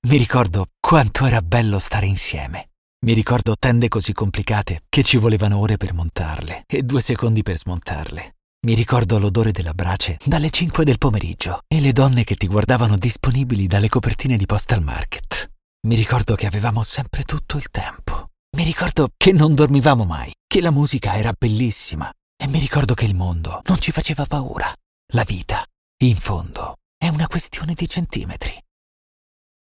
Commerciale, Douce, Corporative, Enjouée, Polyvalente